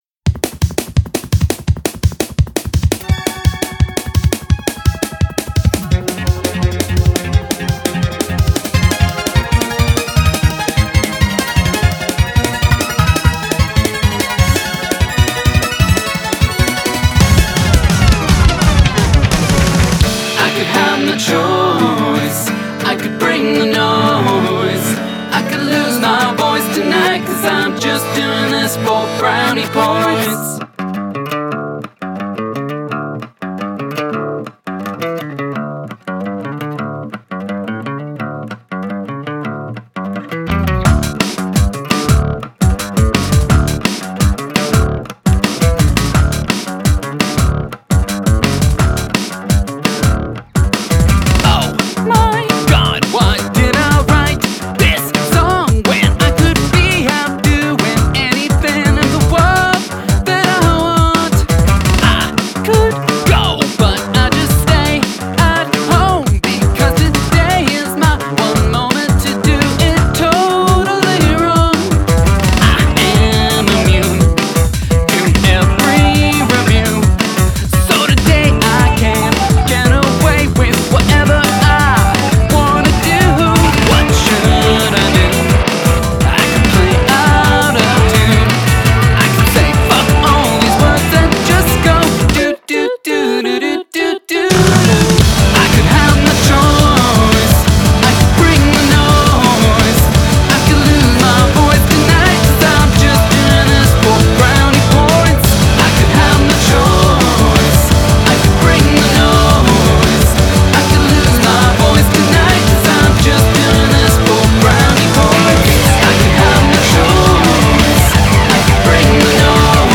Time Signature Changes